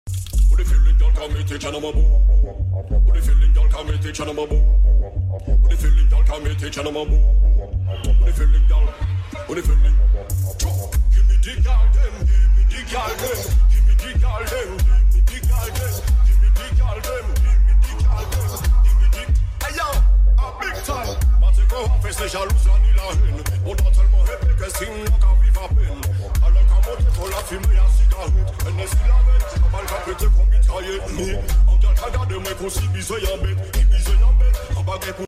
BASS BOOSTED